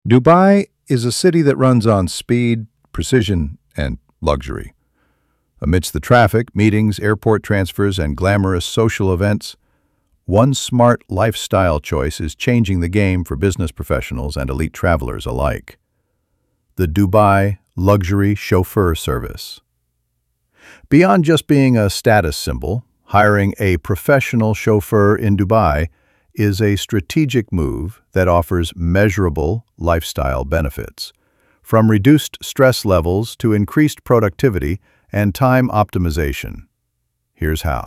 ElevenLabs_Text_to_Speech_audio-9.mp3